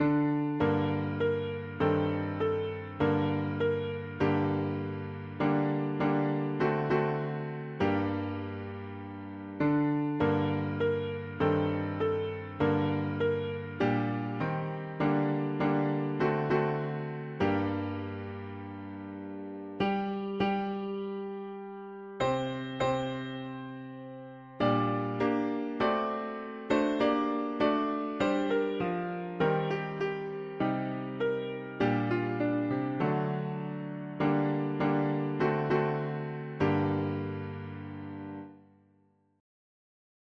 Go down, Moses, way down in Egypt’s la… english christian 4part chords
African American spiritual
Key: G minor